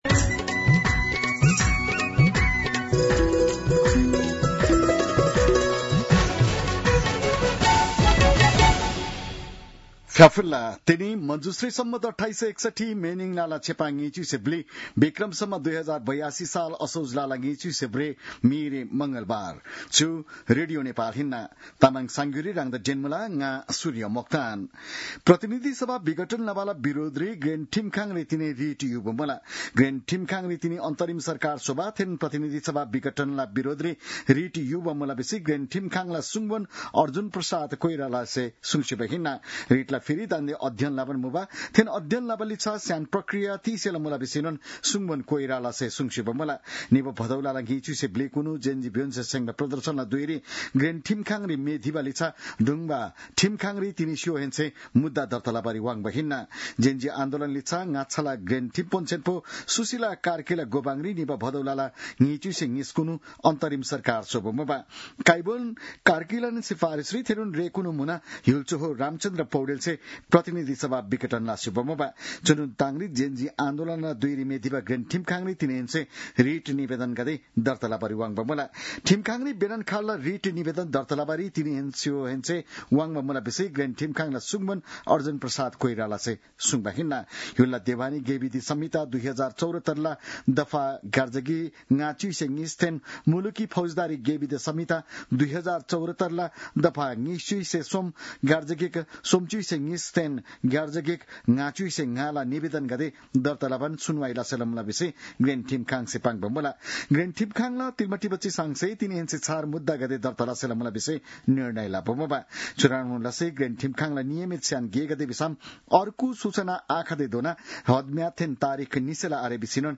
An online outlet of Nepal's national radio broadcaster
तामाङ भाषाको समाचार : २८ असोज , २०८२